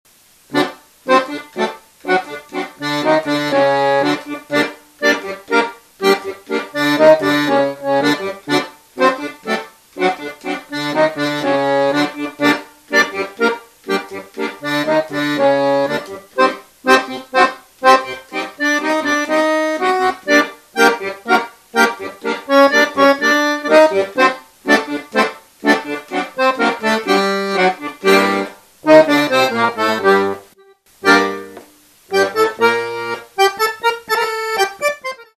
This is a unique kind of accordion with the key colors reversed - the white keys are jade black, and the black keys are white.
41 treble keys and 120 bass buttons Fast action 18 inch size keyboard with color-reversed keys and shinny plexi glass key tops that look like new forever. 3 sets of treble (LMH) reeds and 5 sets bass reeds with extremely powerful sounds.
Tango.mp3